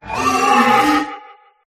drakloak_ambient.ogg